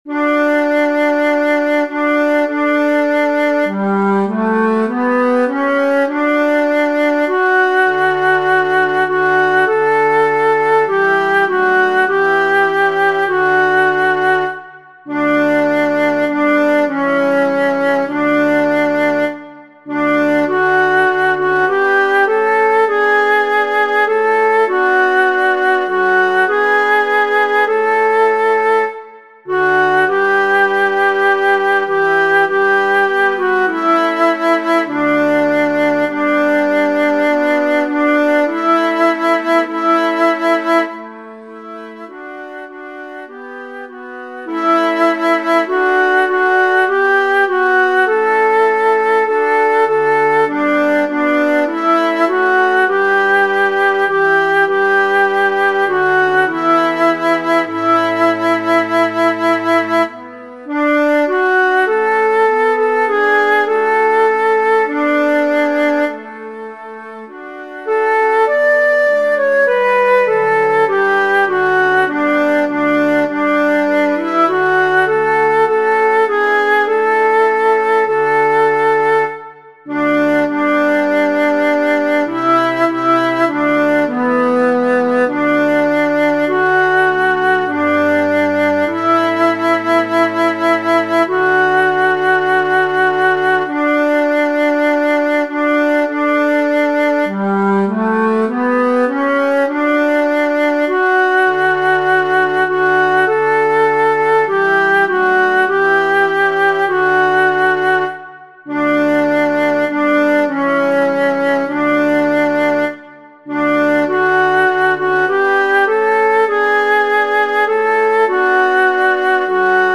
Para aprender la melodía podéis utilizar estos enlaces instrumentales en formato MP3:
Pan divino A MIDIDescarga
pan-divino-a-midi.mp3